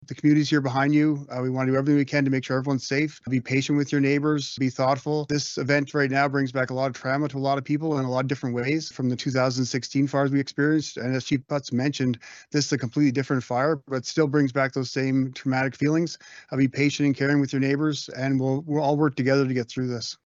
Sandy Bowman, Mayor of the RMWB attended the press conference and wanted to ensure the safety for their residents and acknowledged this could be a difficult time for anyone who may have dealt with the 2016 wildfires.